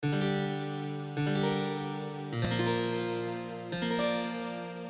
Но это ж не дело так долбаться 10 лет. на примере между 2 и 3 секундой низкочастотный щелчок Вложения глитч.mp3 глитч.mp3 153,5 KB · Просмотры: 2.659